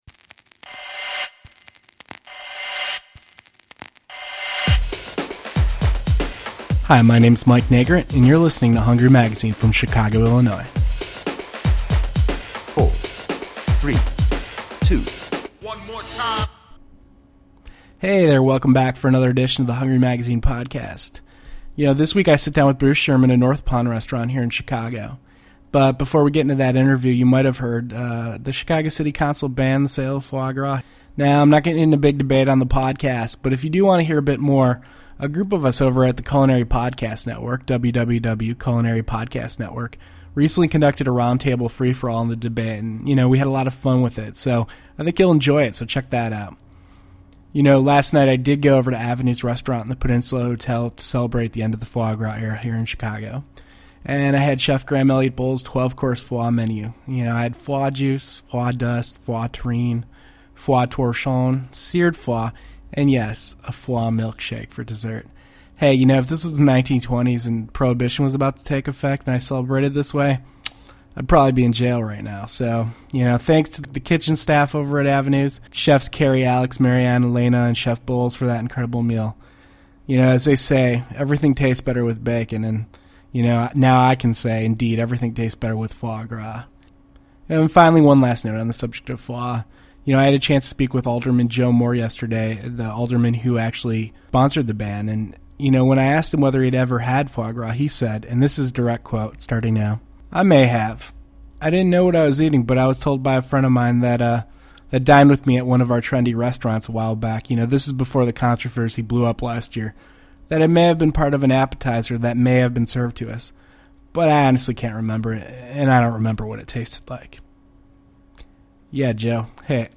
In this week's podcast interview